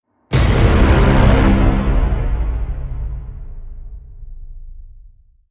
Sms Freaky Horn - Botón de Efecto Sonoro